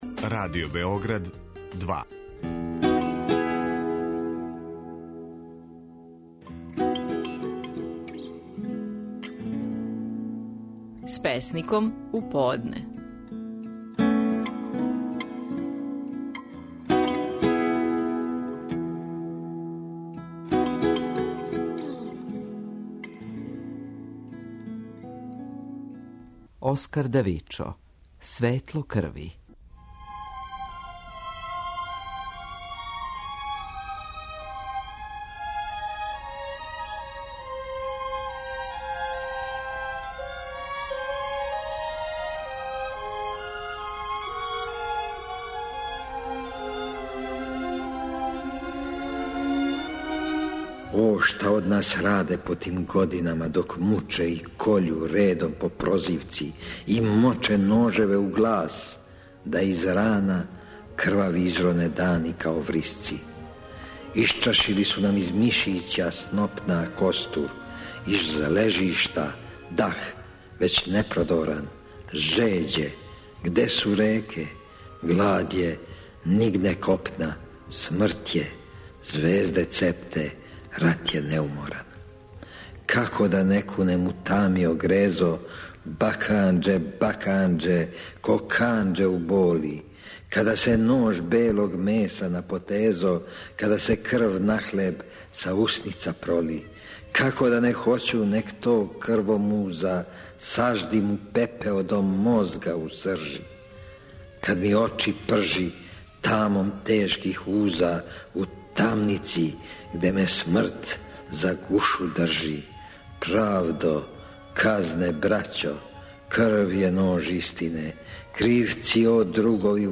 Стихови наших најпознатијих песника, у интерпретацији аутора.
Оскар Давичо говори своју песму "Светло крви".